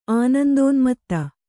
♪ ānandōnmatta